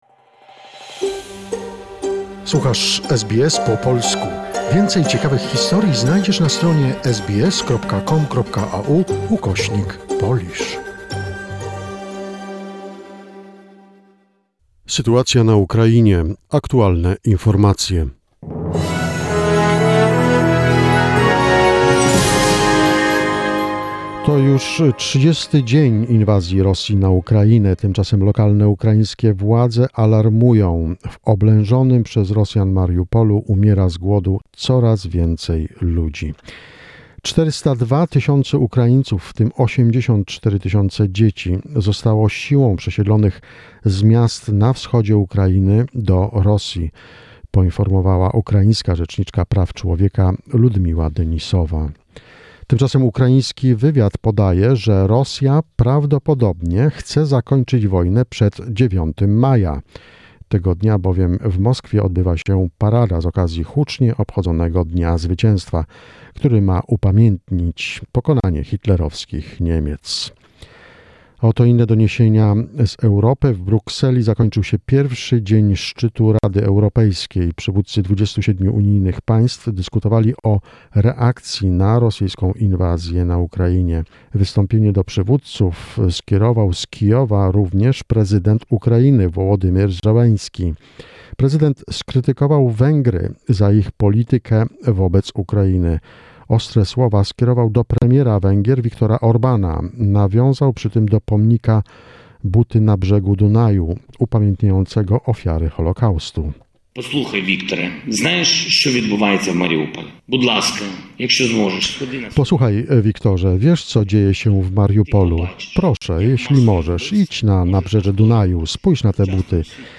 The most recent information about the situation in Ukraine, a short report prepared by SBS Polish.